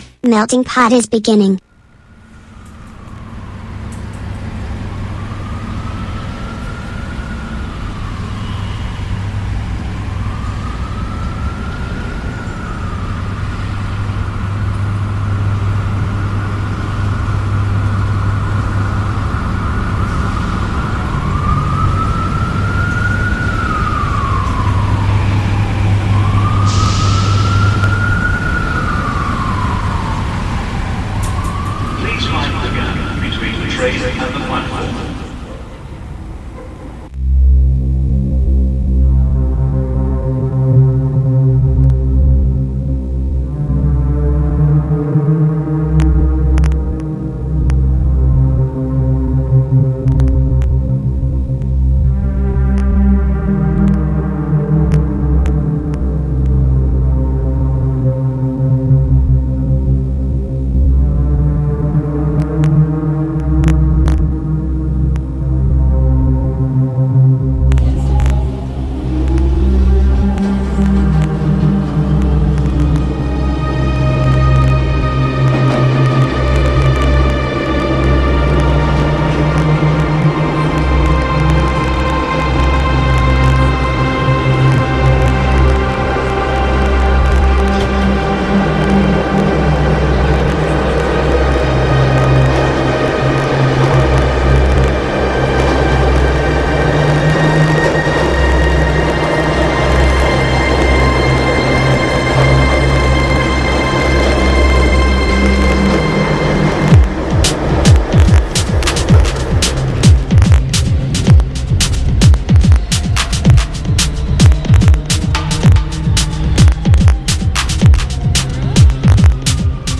Nella puntata del 10 dicembre Melting Pot ha intrecciato come sempre attualità, musica e curiosità, attraversando scenari globali e sonorità che spaziano dal jazz contemporaneo alla techno più ruvida.